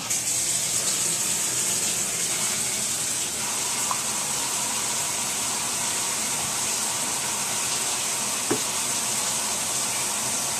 washing5.ogg